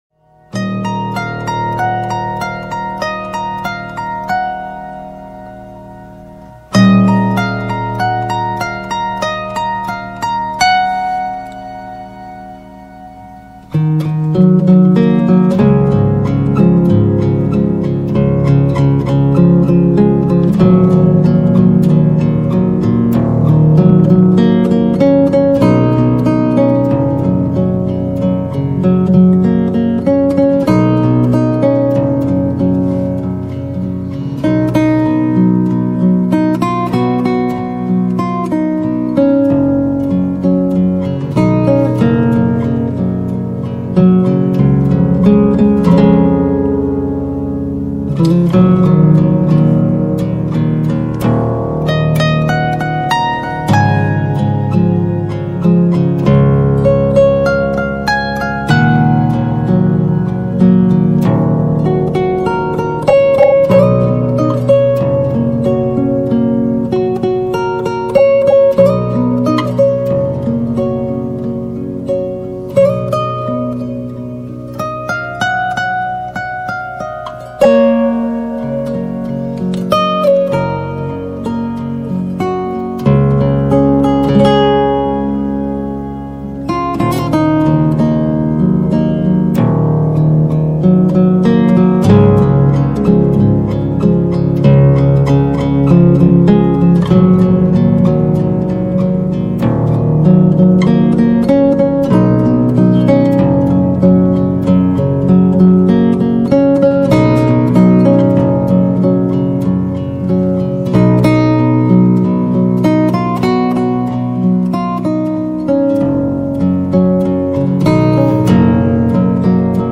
18 String Harp Guitar Cover